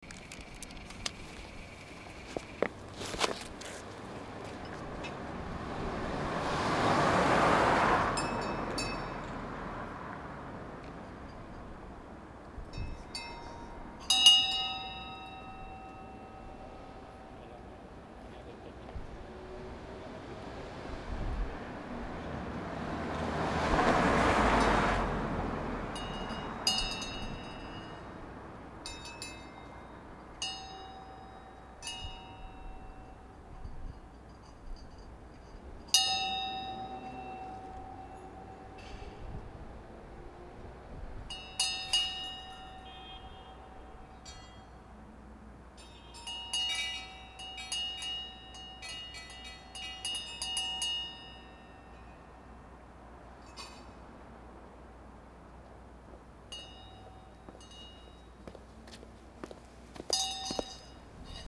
Here's an audio recording from the evening, for a change.